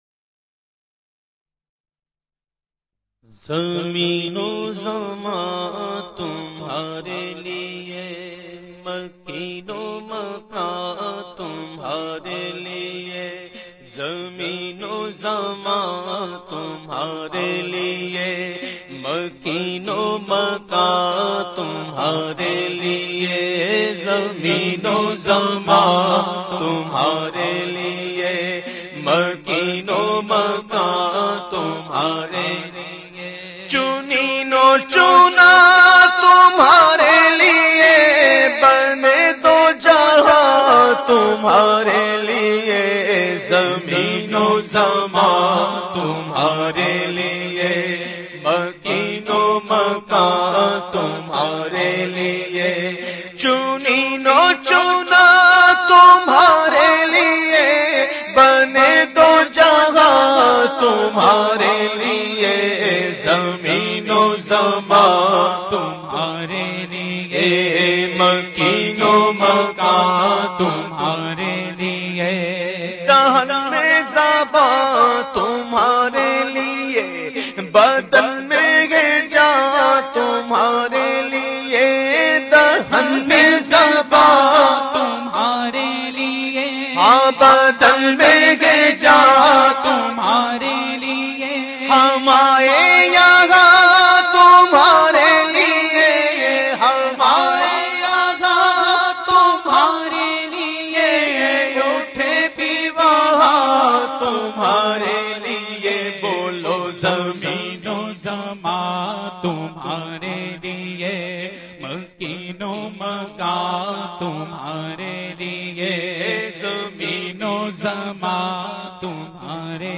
The Naat Sharif Zameen o Zaman Tumhare Liye recited by famous Naat Khawan of Pakistan owaise qadri.